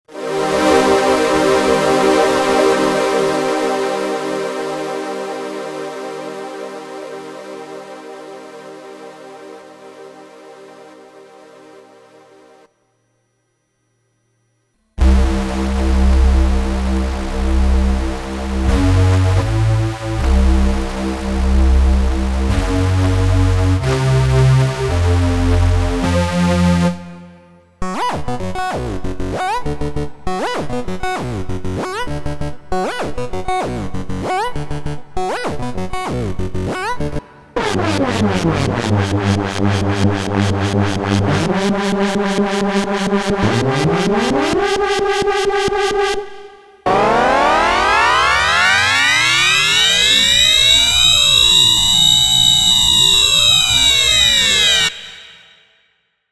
here you go, 50 seconds of random life affirming noises made by between 1 & 8 SID chips stuck through a random ableton reverb setting.